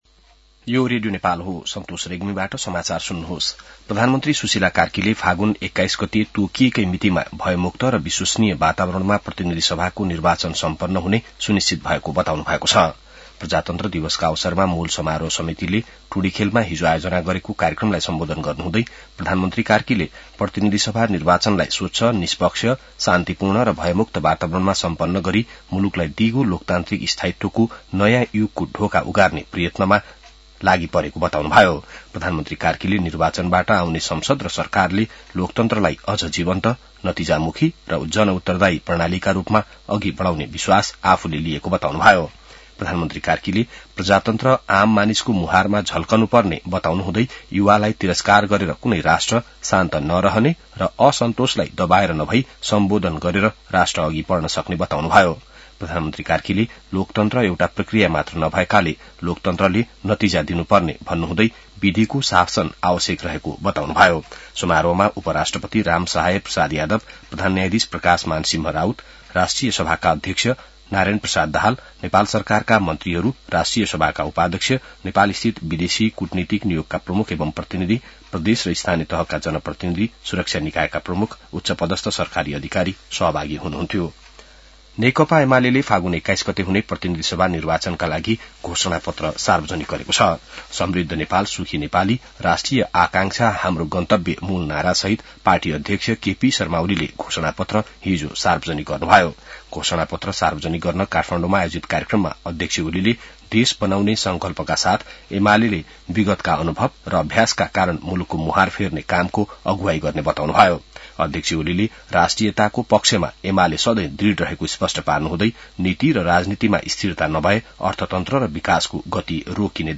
बिहान ६ बजेको नेपाली समाचार : ८ फागुन , २०८२